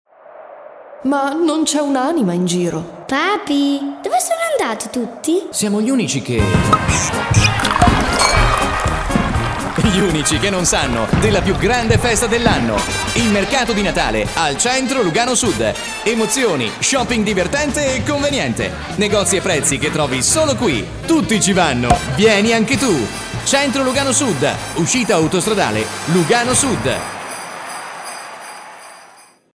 Voce frizzante, importante, per promo, spot radio e tv, trailers, cortometraggi, redazionali, messaggi promozionali, voice over, station id e tanto altro
Sprechprobe: Industrie (Muttersprache):
I'm a very important speaker all over Italy...my voice is hot, young and versatile for any kind of production